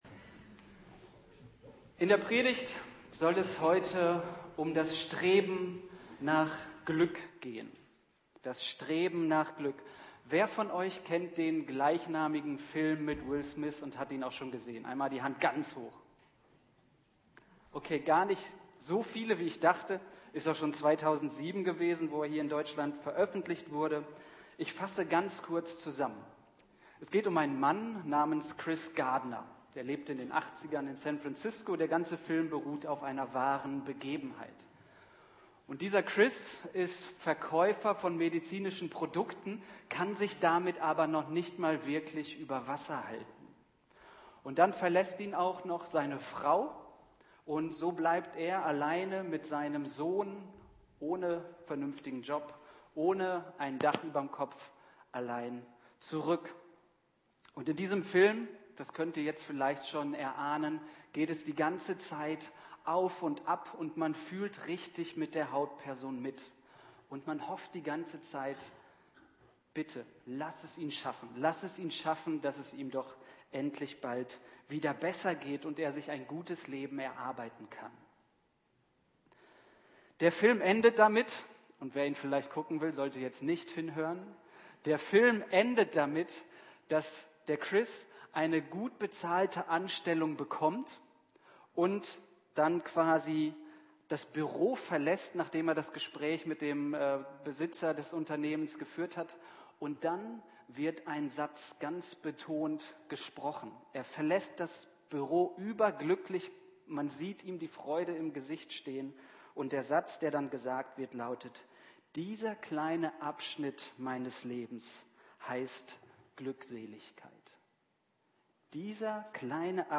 Der Gottesdienst wurde musikalisch begleitet von Bezirksbläser Heidelberg
Predigt